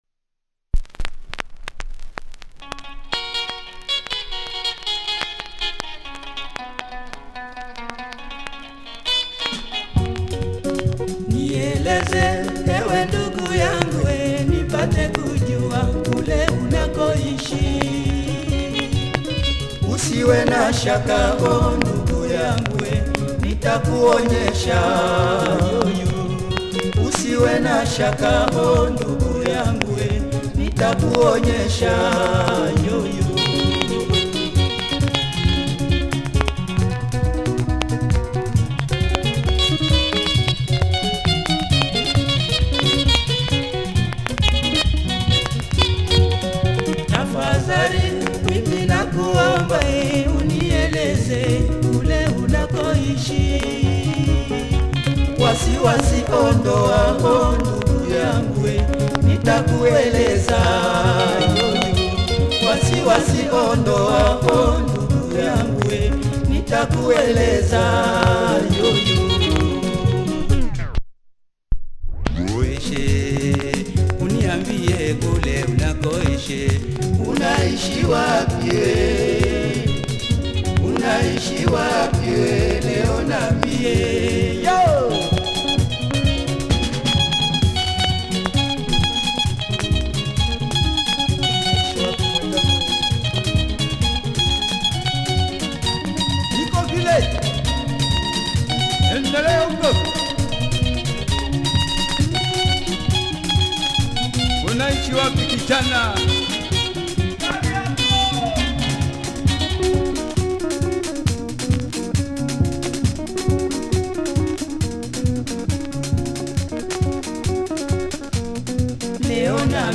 Nice soukouss doublesider
Plays with some noise, check audio of both sides.